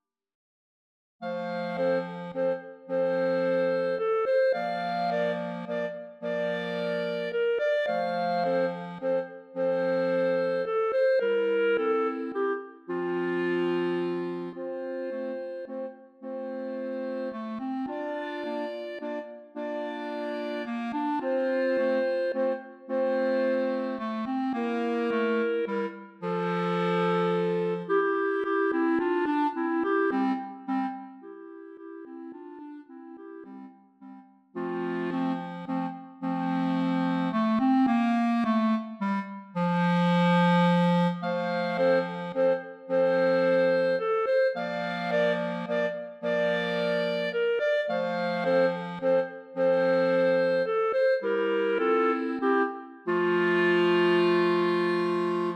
Voicing: Clarinet Trio